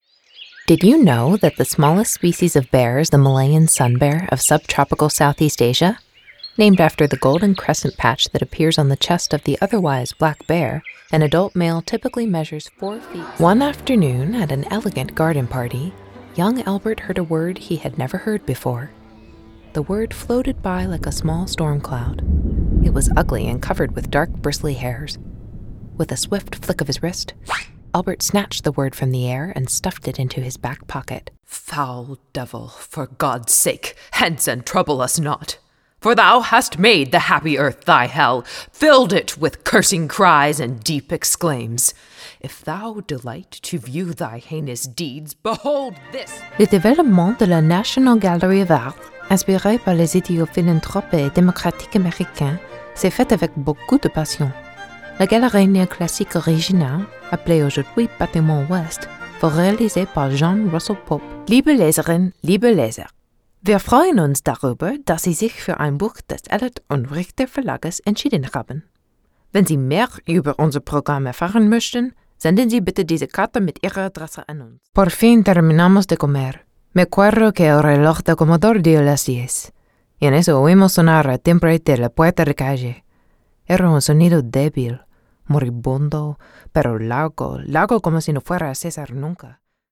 Female Voice Over, Dan Wachs Talent Agency.
Warm, Friendly, Conversational
Narration